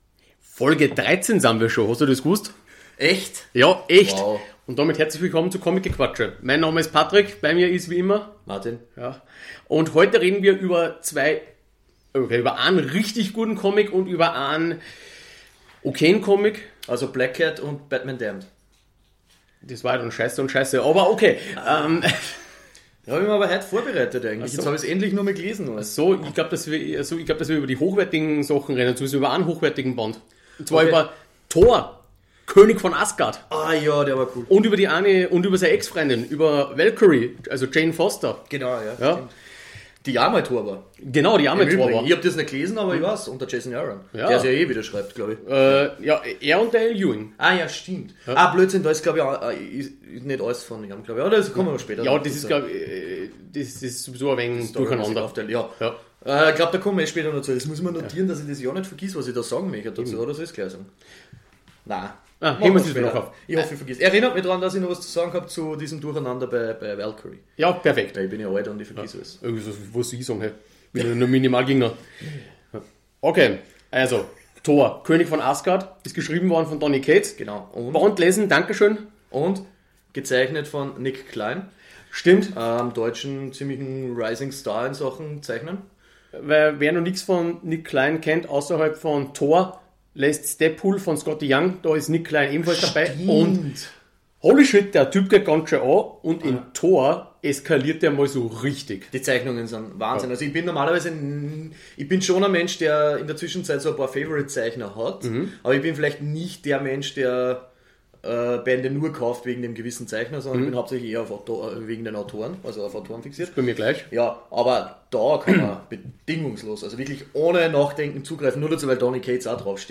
Comic-Talk